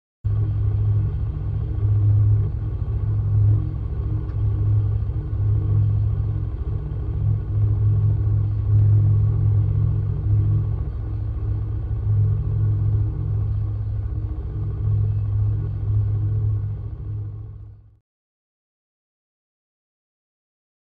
Machine Hum With Very Low Frequency.